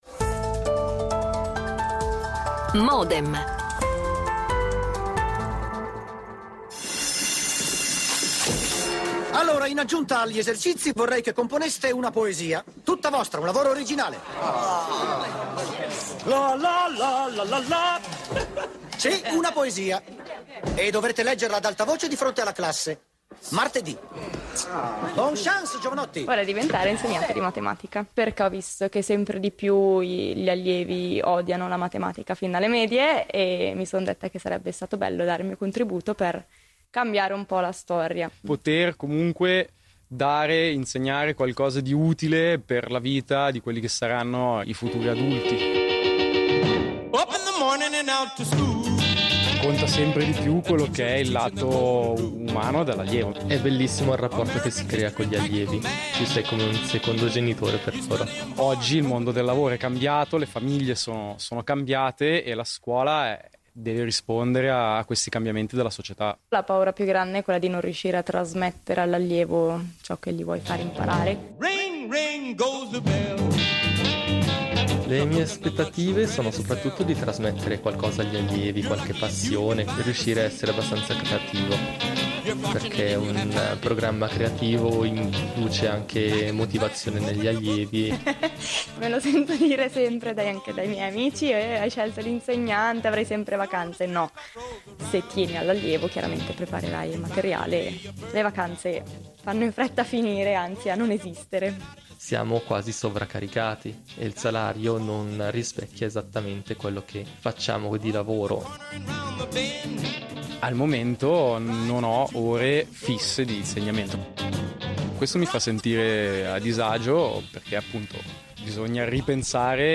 Modem mette a confronto docenti e specialisti: